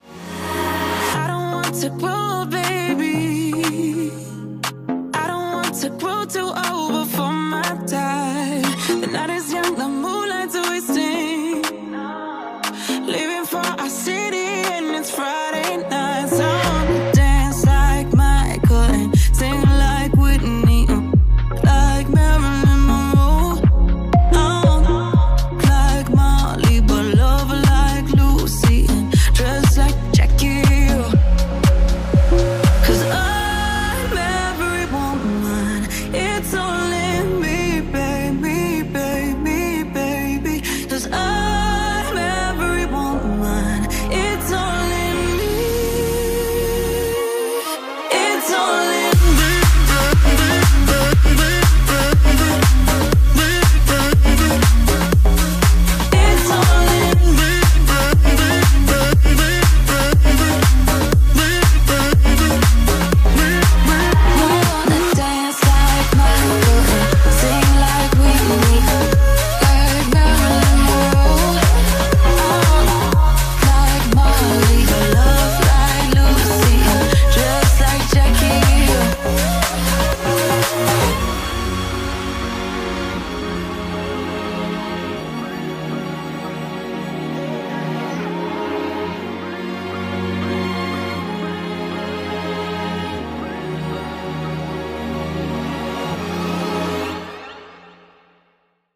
BPM120
Audio QualityPerfect (High Quality)
Censored audio